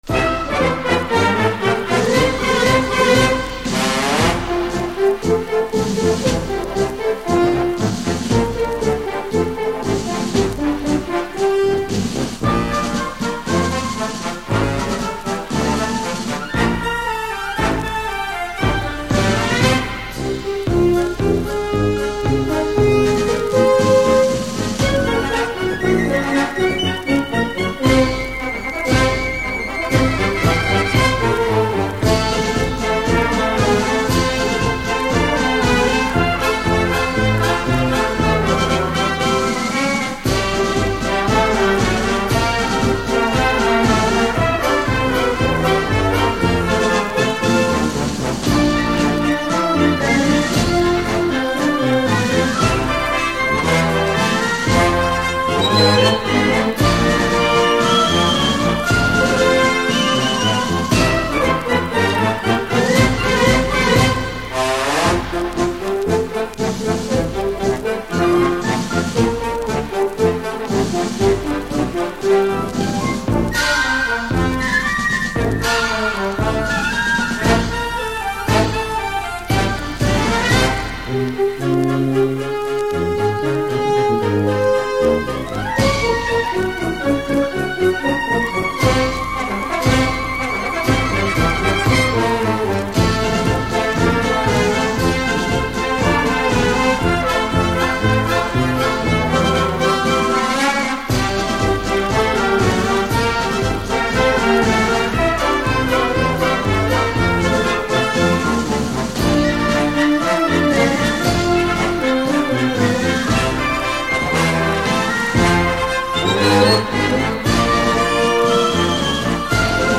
VERSIONS INSTRUMENTALES